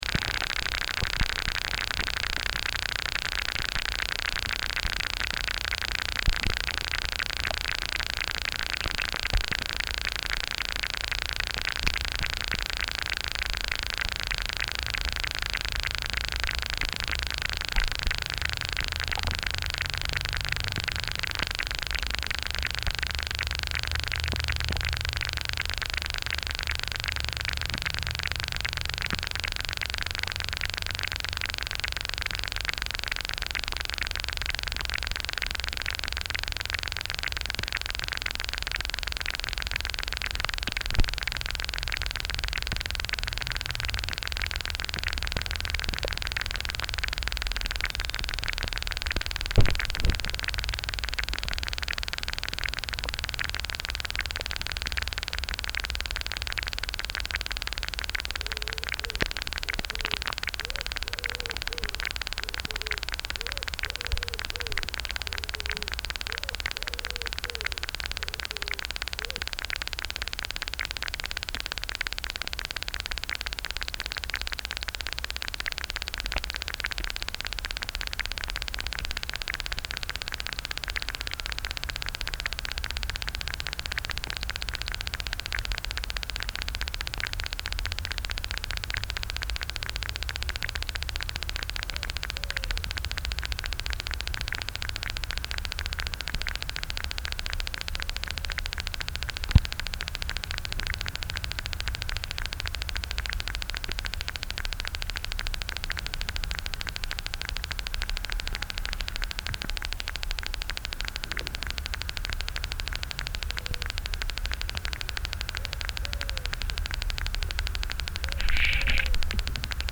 Aquatic insect (decelerator) (2 extracts) – Brook close to the Route des Batteries – September 2009
The recording has been made at several times, in the same place, in July, end of August and mid-September.
Sample 3, the decelerating insect sound, has been used in a reverse way in the composition.